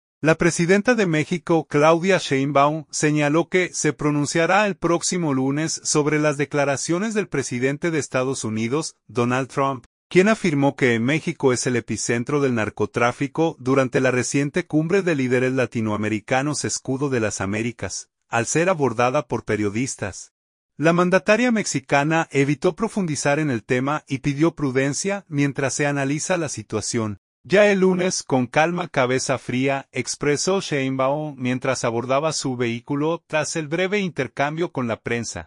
Al ser abordada por periodistas, la mandataria mexicana evitó profundizar en el tema y pidió prudencia mientras se analiza la situación.
“Ya el lunes, con calma… cabeza fría”, expresó Sheinbaum mientras abordaba su vehículo tras el breve intercambio con la prensa.